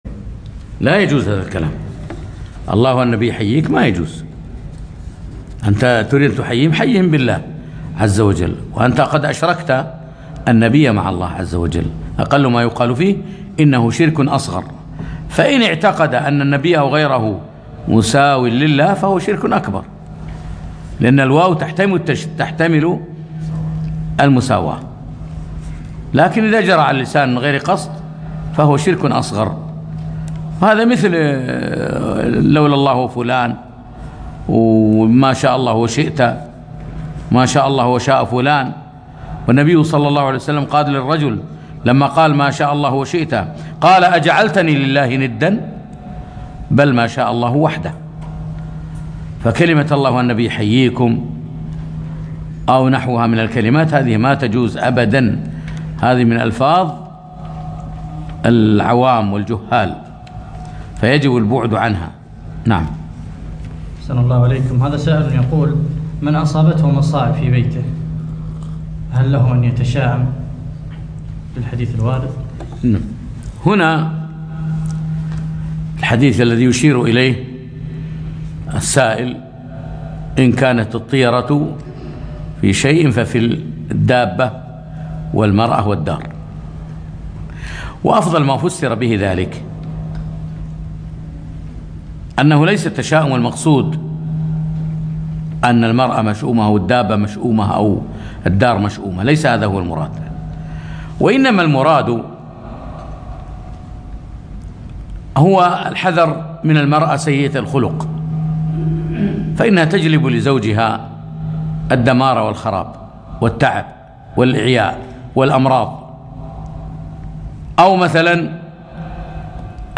8- الأسئلة بعد الدرس